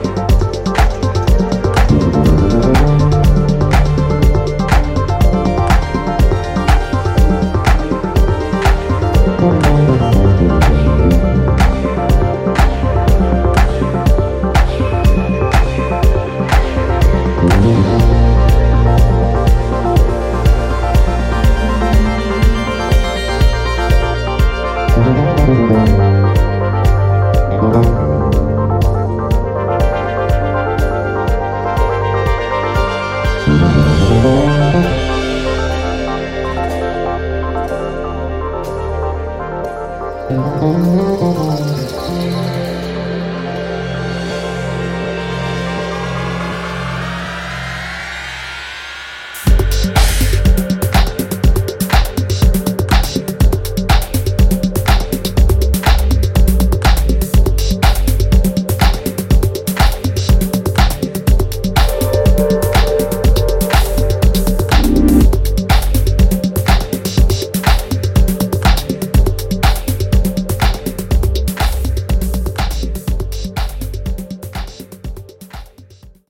トリッピーで浮遊感溢れるコードと808グルーヴにメロディアスなベースラインを添えた引力抜群の
いずれもミニマルでありながらも捻りの効いた内容で、ディープ・ハウス路線のセットで助けられることも多いはず。